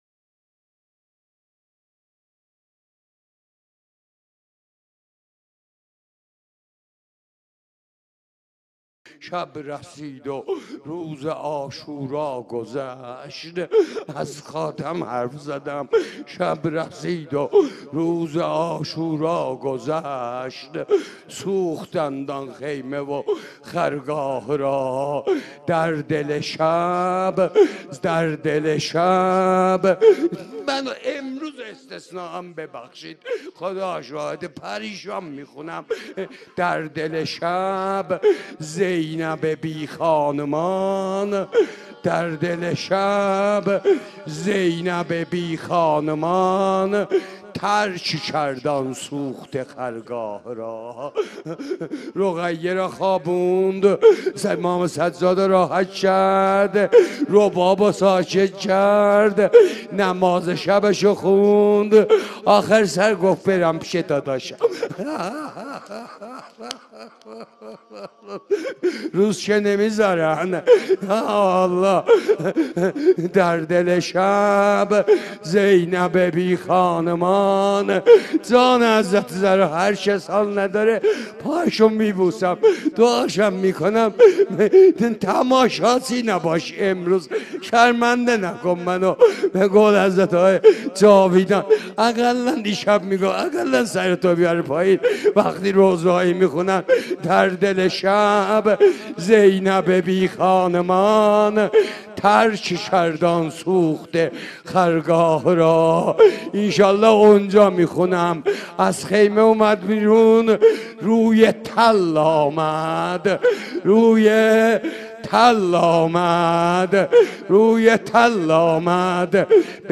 روضه | شب رسید و روز عاشورا گذشت